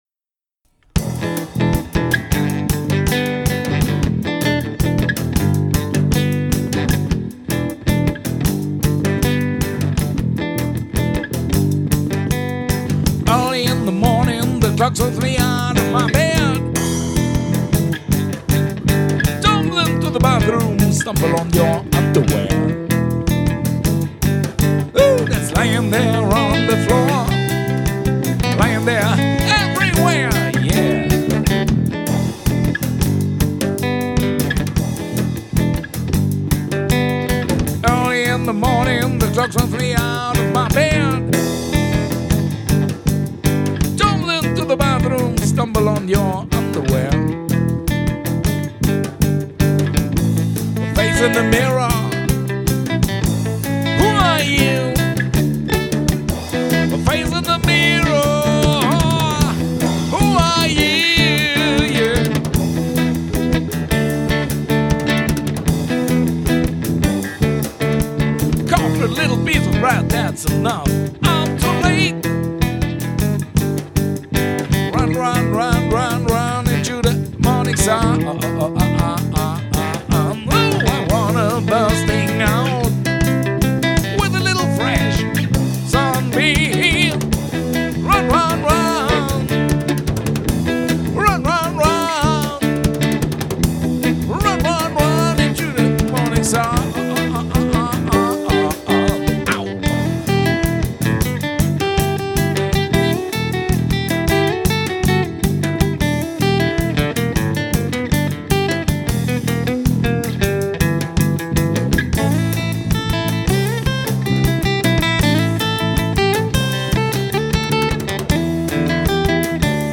Unplugged Set - live aufgenommen im Studio